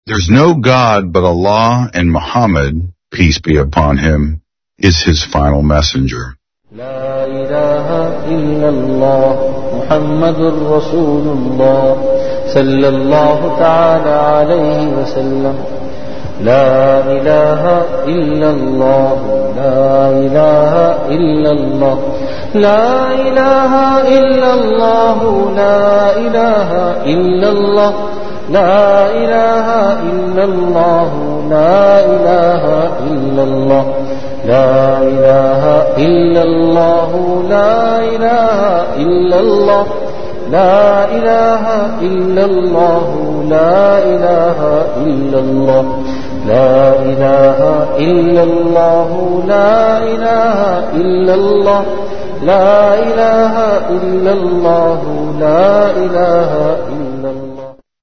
CategoryZikr
VenueJamia Masjid Bait-ul-Mukkaram, Karachi